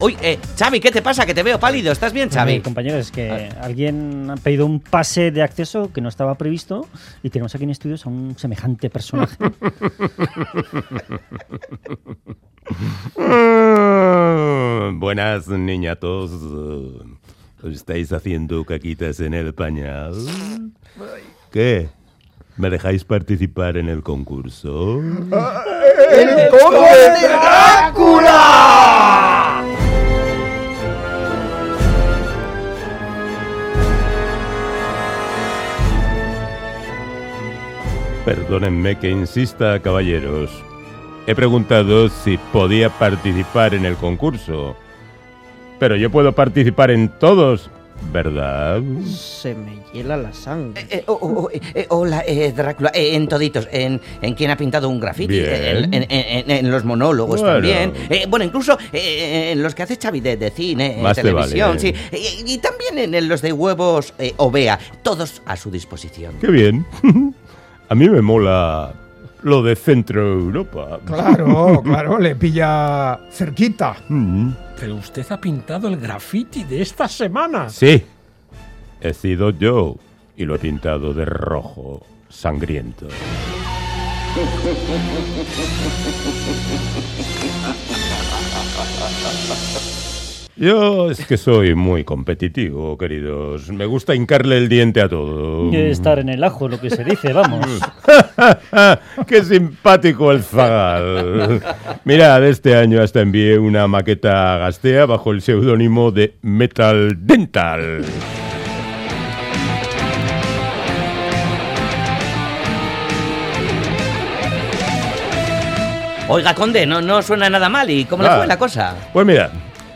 Radio Euskadi GRAFFITI Escabechina monumental en pleno Graffiti Última actualización: 11/05/2018 18:28 (UTC+2) La aparición, inesperada, del Conde Drácula, en directo, termina en una auténtica sangría tras el relato de la fracasada vida del histórico vampiro que casi suplica por participar en el concurso Whatsapp Whatsapp twitt telegram Enviar Copiar enlace nahieran